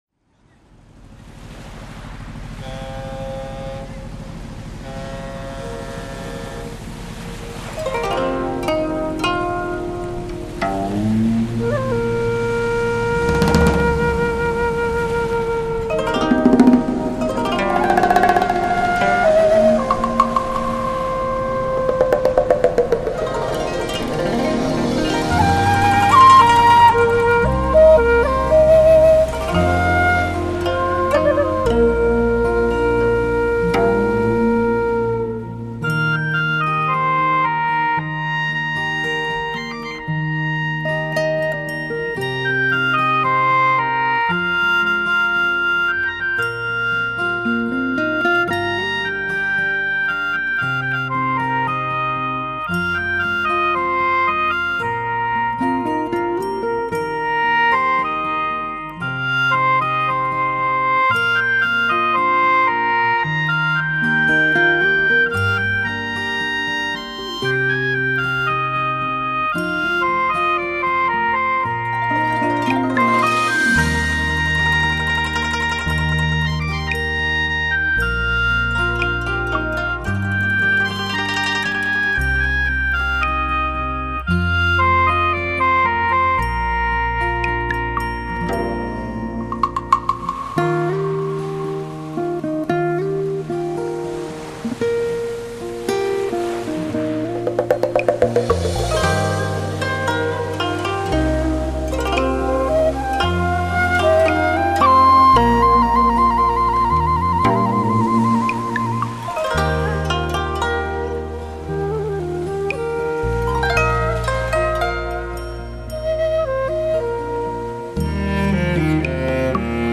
HI-FI典范，如歌旋律 完美演奏，完美的录音浑然天成。
音域宽广，却又细腻绵绵，音质清纯之美令人陶醉，营造出一个充满活力的HIFI场面，
琵琶弹起，淡淡的， 像是月色轻洒在湖面，波光粼粼；
二胡拉起，如泣如诉、如悲似怒，时而委婉低回，时而激越高亢，